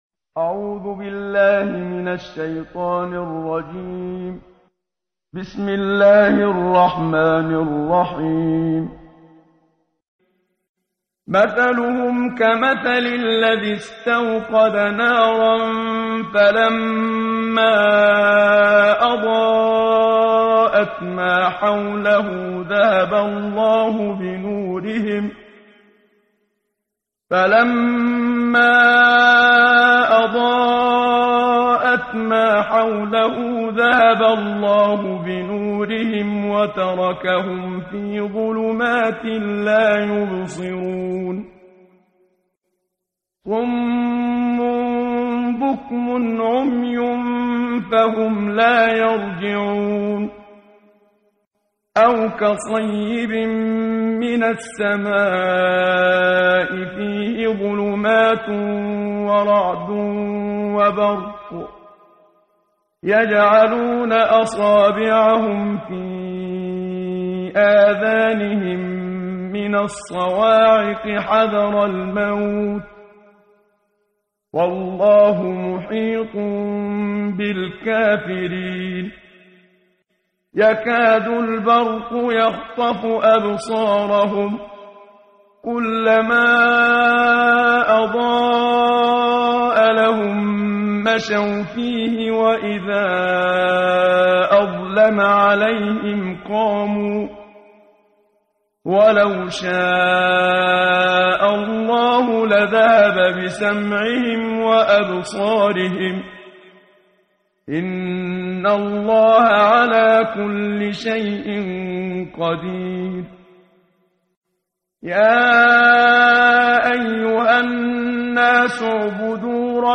قرائت قرآن کریم ،امروز، صفحه چهارم، سوره مبارکه بقره آیات هفدهم تا بیست و چهارم با صدای استاد صدیق منشاوی.